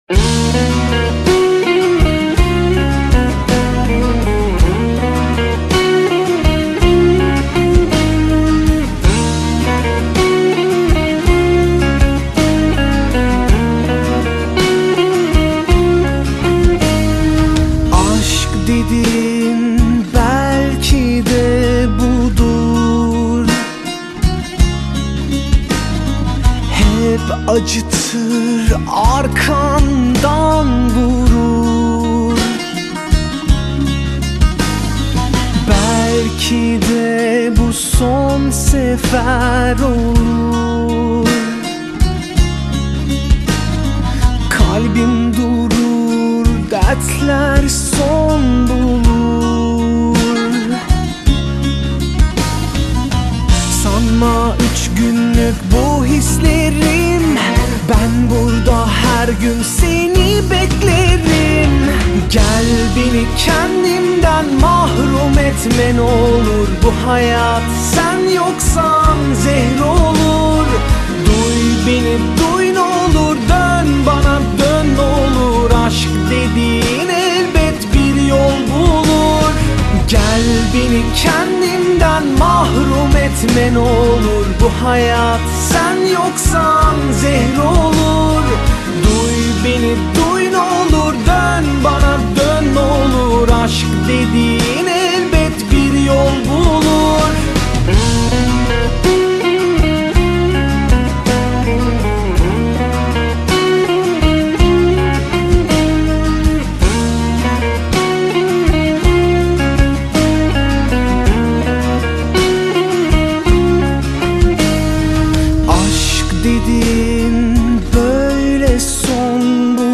Bu bölümde yapay zekânın günlük yaşam, iş dünyası ve eğitim üzerindeki etkilerini ele alıyoruz. Uzman konuk ile gelecekte bizi bekleyen teknolojik dönüşümleri değerlendiriyoruz.